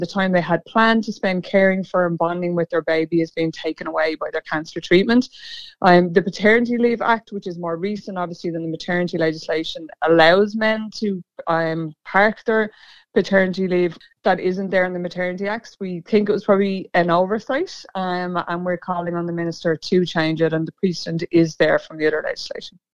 CEO Averil Power says changes to legislation would be very easily achieved……….